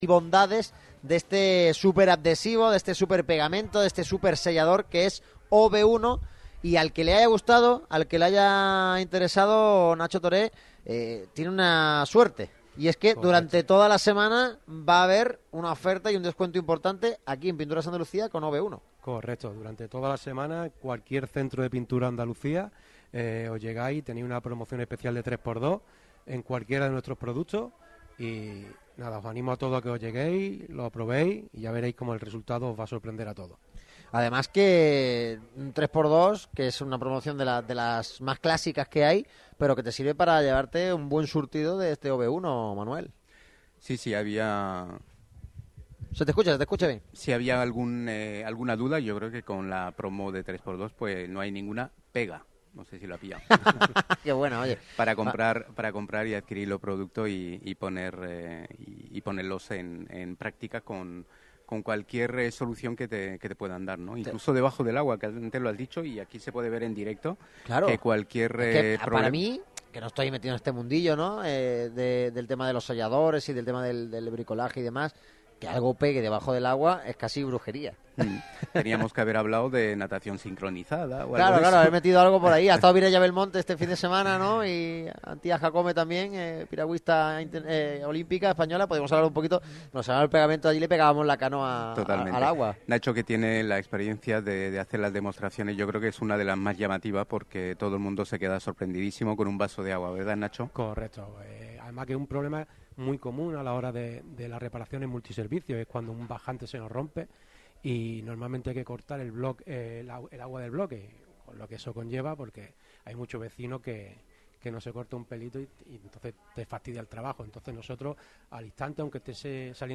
El equipo de Radio MARCA Málaga sigue moviéndose, y esta misma mañana ha sido acogido, de la mano de Grupo PROTEC, en uno de los establecimientos de Pinturas Andalucía.